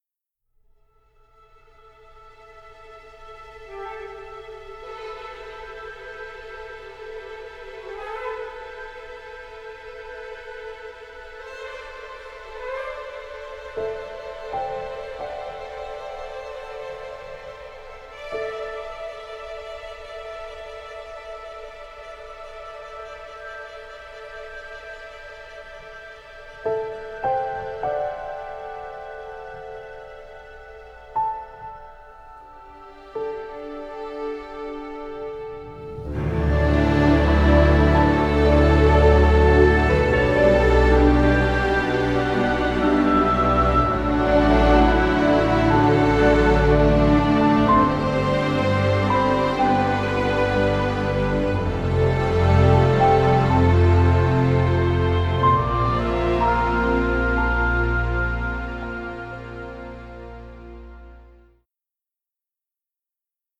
orchestral score which offers extremely wide range of colors